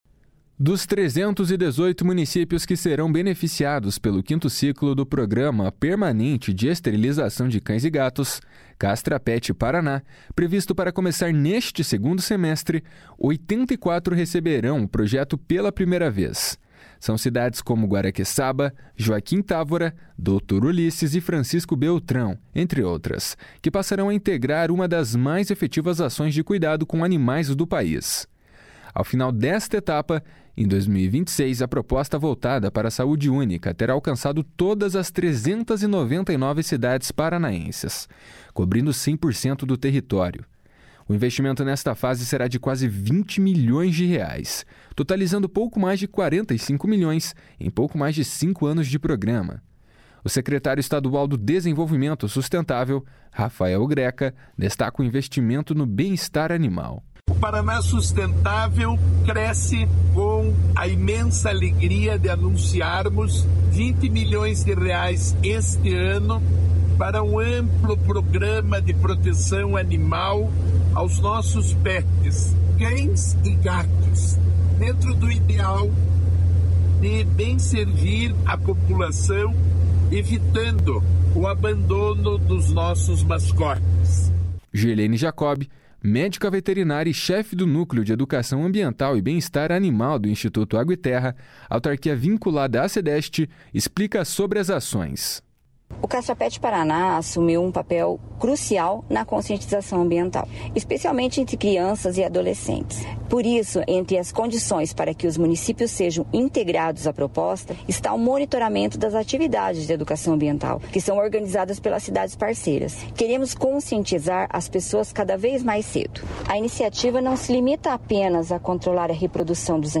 O investimento nesta fase será de quase 20 milhões de reais, totalizando pouco mais de 45 milhões em pouco mais de cinco anos de programa. O secretário estadual do Desenvolvimento Sustentável, Rafael Greca, destaca o investimento no bem-estar animal.